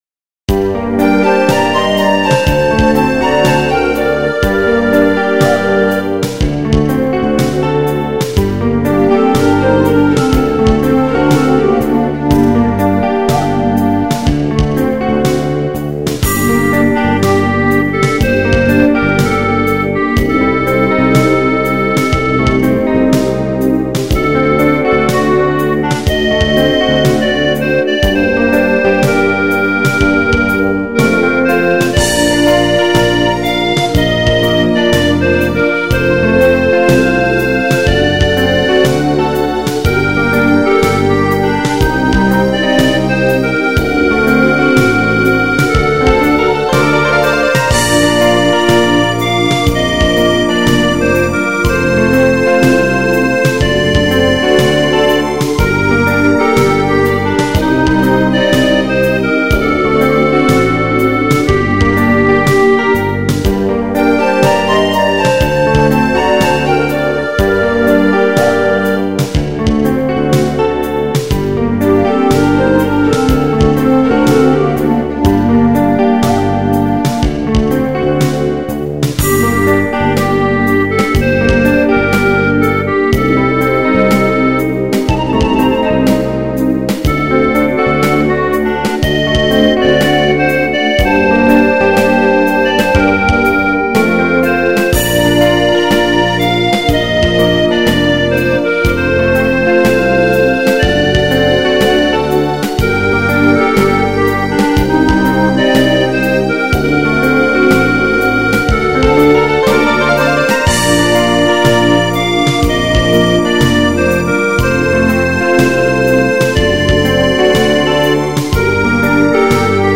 • Песня: Юмор